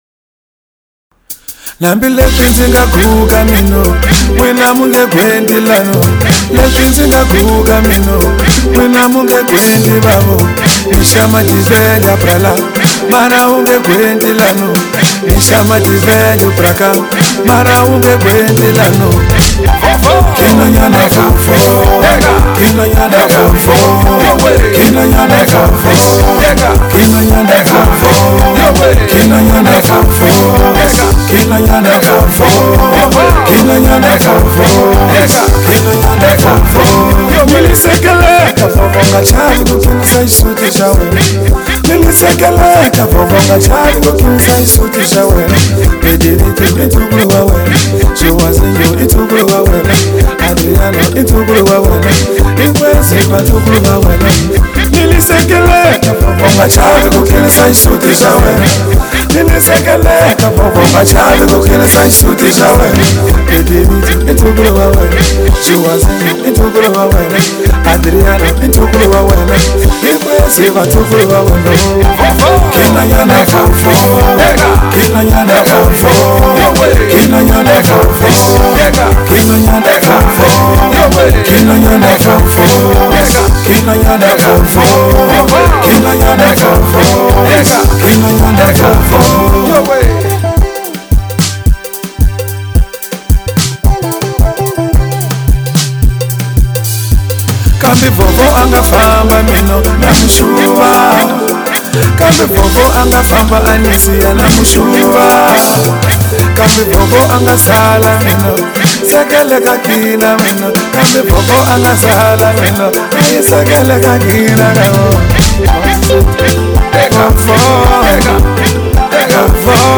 Genero: Pandza